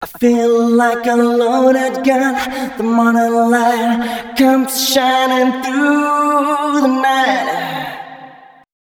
005 male.wav